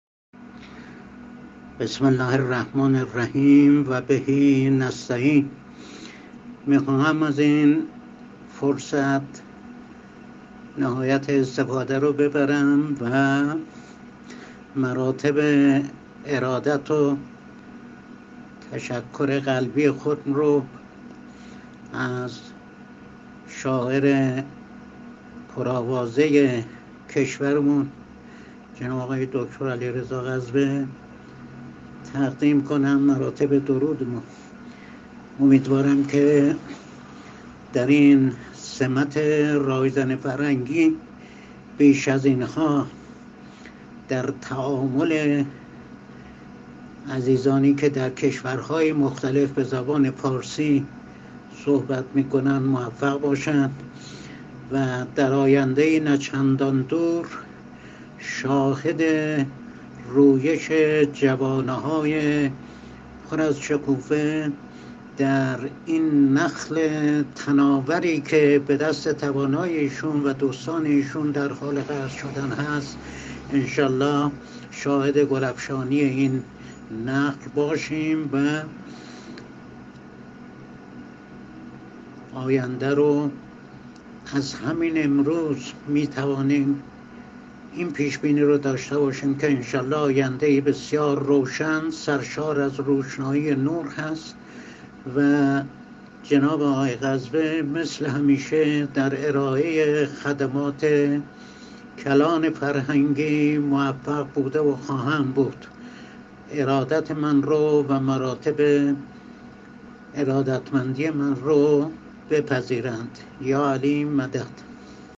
پیام پدر شعر آیینی ایران
پیام صوتی استاد محمدعلی مجاهدی، پدر شعر آیینی ایران در مراسم بزرگداشتش توسط گروه بین‌المللی هندیران.